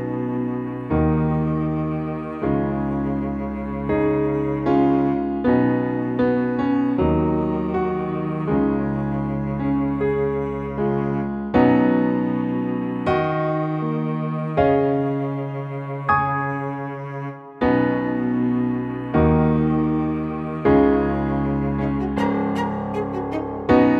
Up 3 Female Key